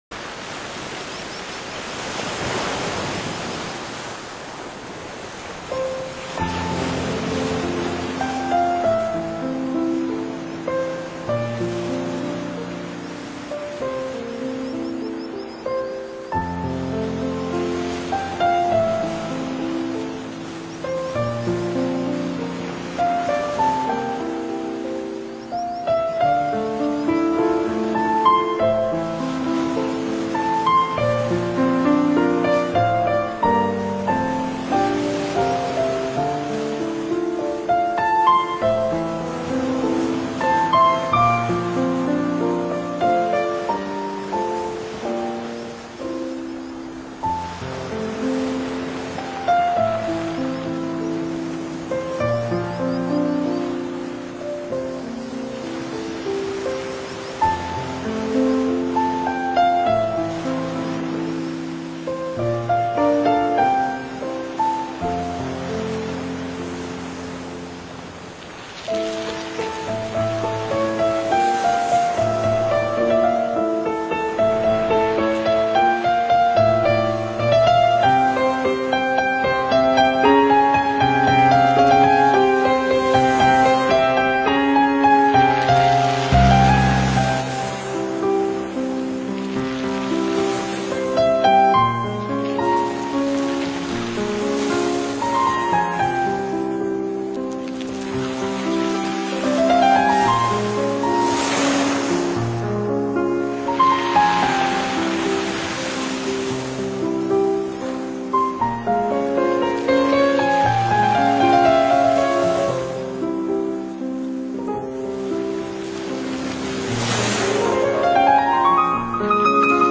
台湾沿海实地录音，临场海浪声绝无仅有。
钢琴